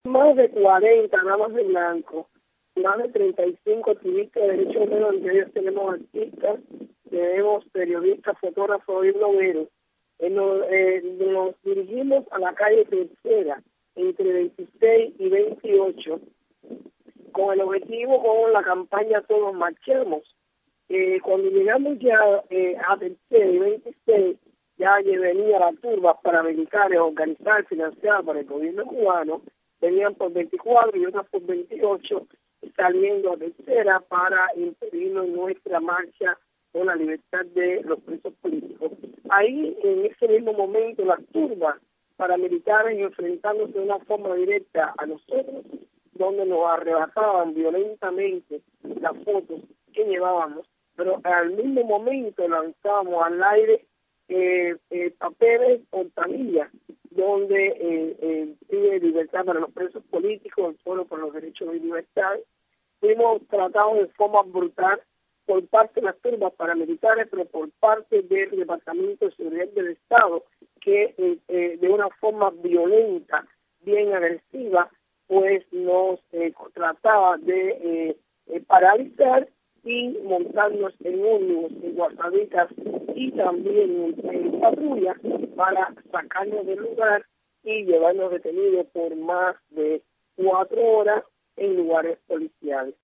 Berta Soler, líder de las Damas de BLanco denuncia detenciones